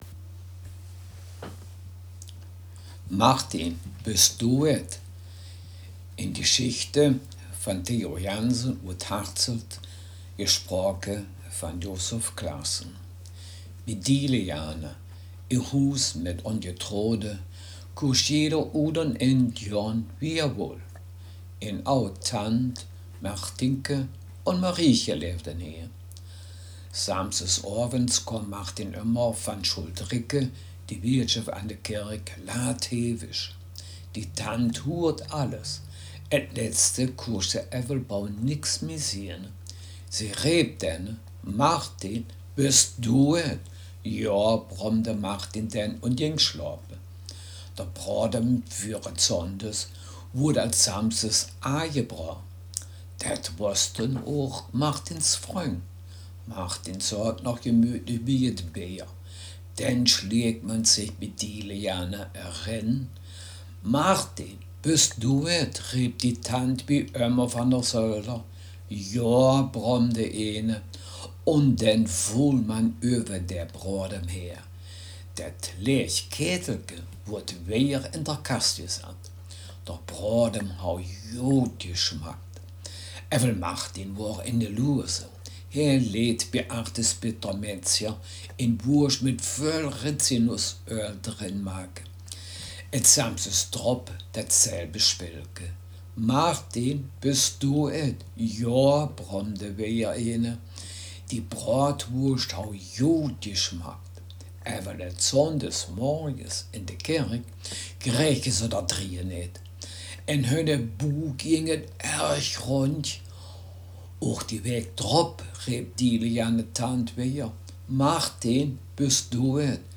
Text Mundart
Gangelter-Waldfeuchter-Platt
Geschichte